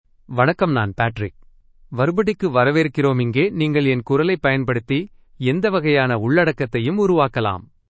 Patrick — Male Tamil AI voice
Patrick is a male AI voice for Tamil (India).
Voice sample
Listen to Patrick's male Tamil voice.
Patrick delivers clear pronunciation with authentic India Tamil intonation, making your content sound professionally produced.